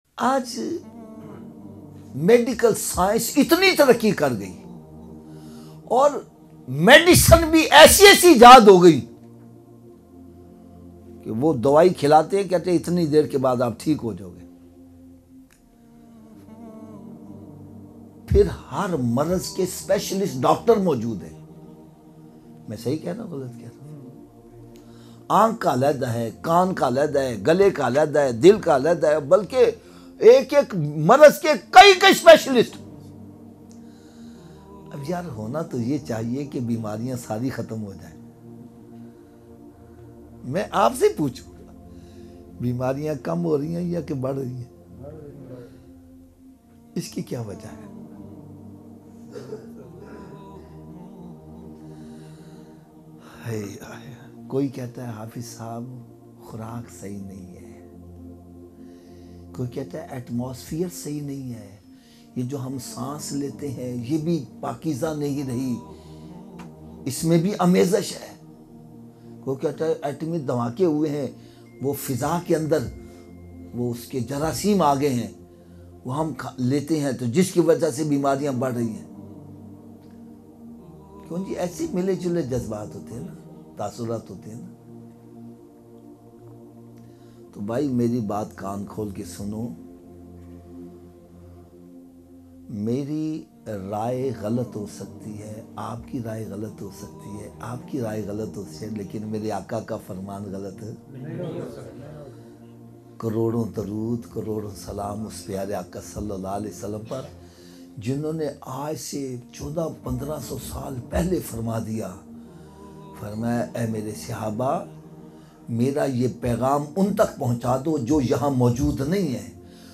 Coronavirus-China-new-bayan.mp3